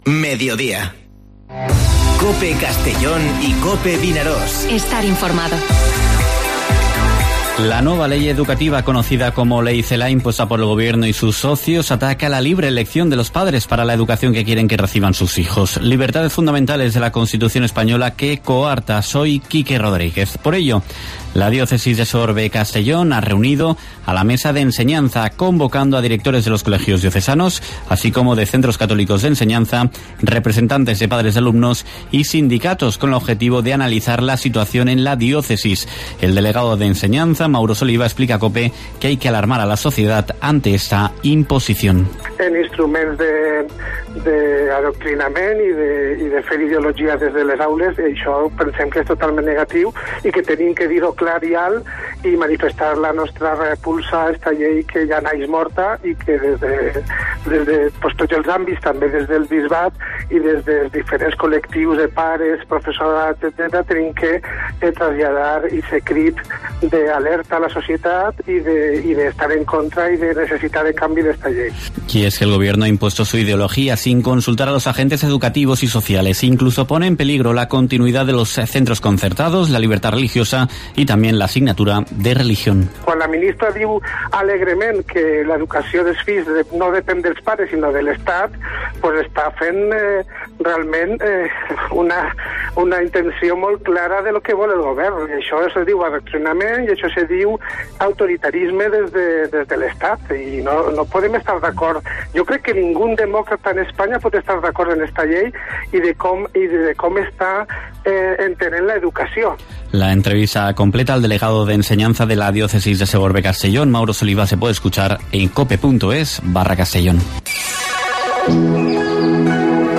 Informativo Mediodía COPE en la provincia de Castellón (10/12/2020)